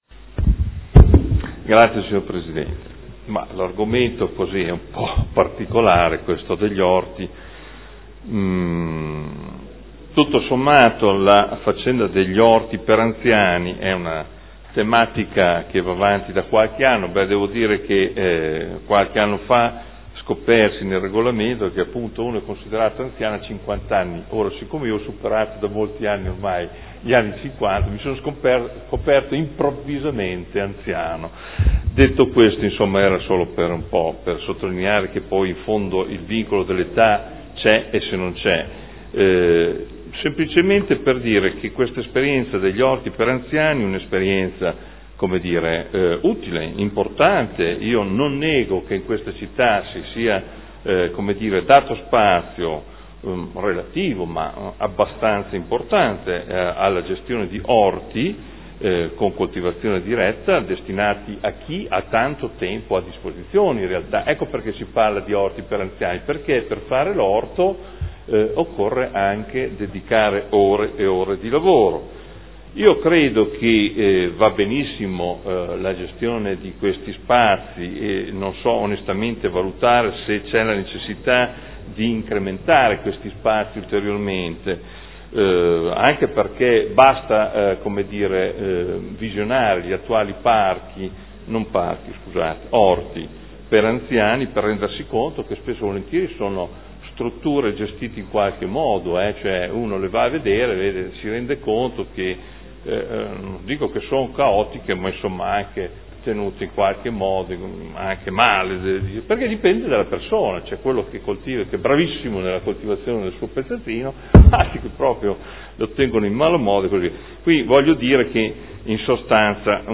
Adolfo Morandi — Sito Audio Consiglio Comunale
Seduta del 29/04/2013 Ordine del Giorno presentato dai consiglieri Urbelli, Rocco, Glorioso, Goldoni, Morini, Trande (P.D.) avente per oggetto: “Orti urbani a Modena”.